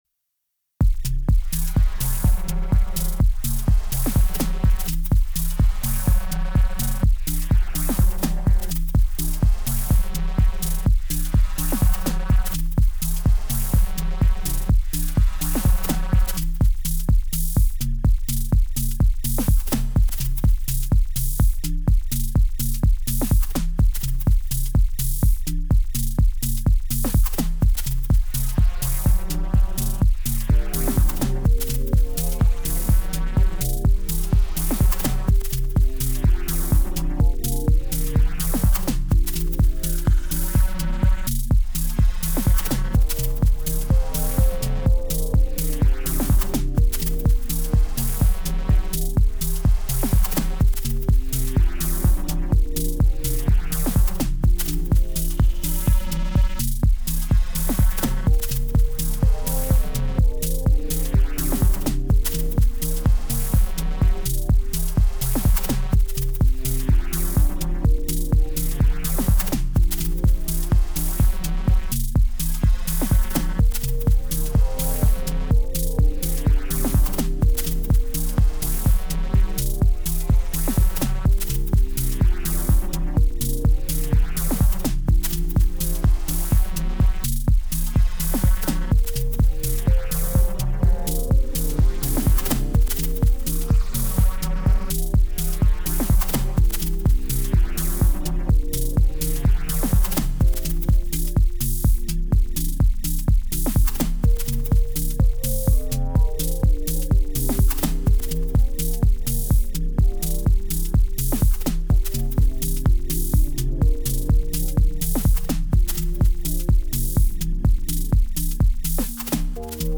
first 4x4 thing on the DTII so wanted to share here
all -> +FX